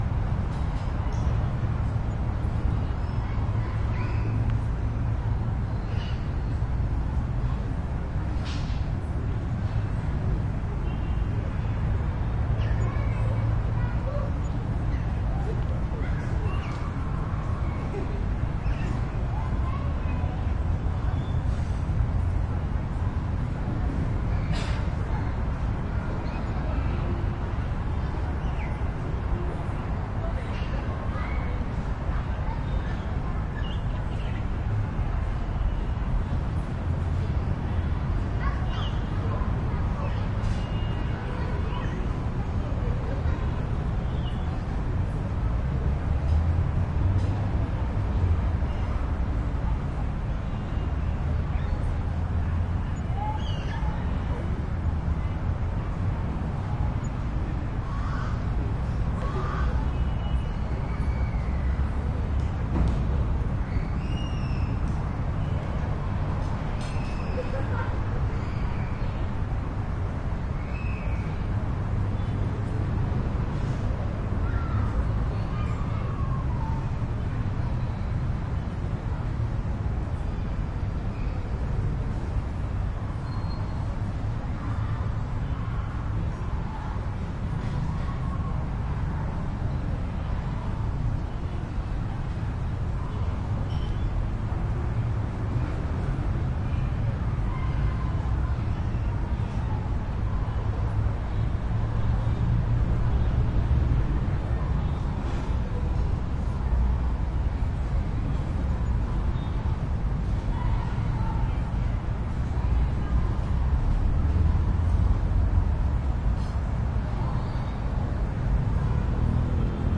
乌干达 " 交通繁忙的天际线，从啤酒花园院子里的树木+遥远的声音和孩子们，坎帕拉，乌干达，非洲
描述：交通沉重的天际线从啤酒花园庭院与树木+遥远的声音和儿童孩子坎帕拉，乌干达，非洲2016.wav
Tag: 乌干达 交通 天际线 庭院 树木 啤酒 非洲 公园 城市